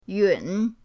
yun3.mp3